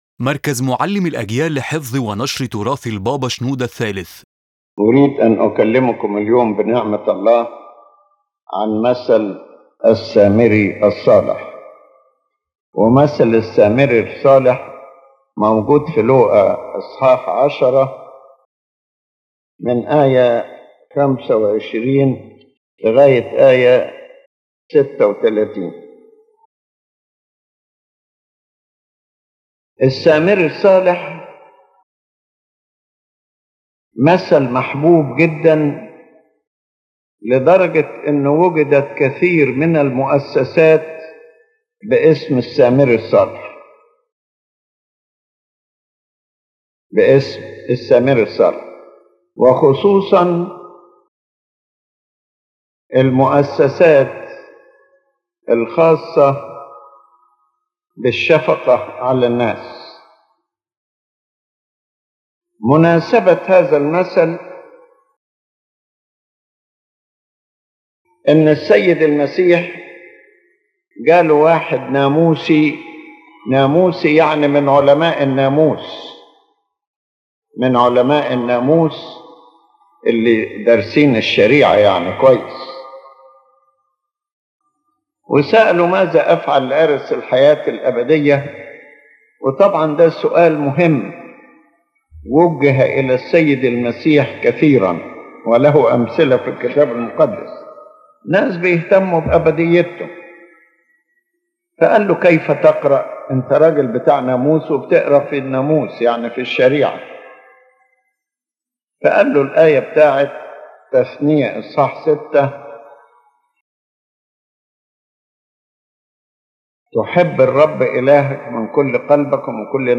In this sermon, Pope Shenouda III speaks about the Parable of the Good Samaritan (Luke 10:25–37), explaining its symbolic and practical meanings.